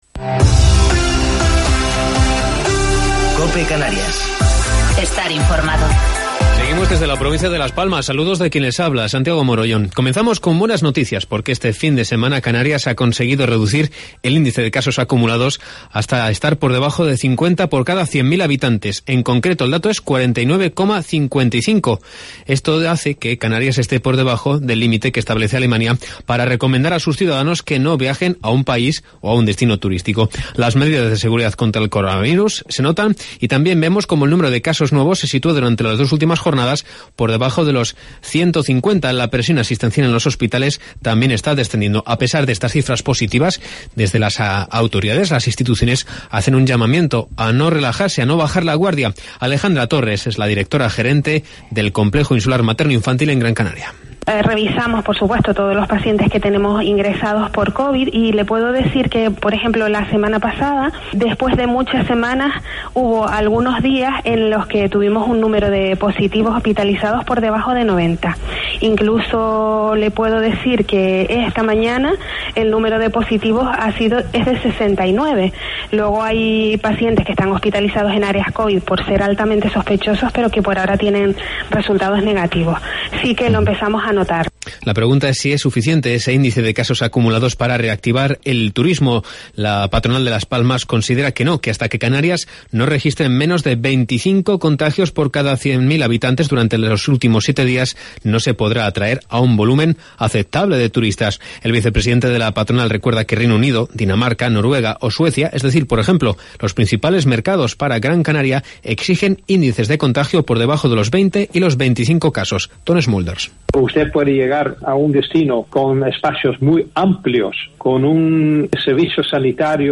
Informativo local 5 de Octubre del 2020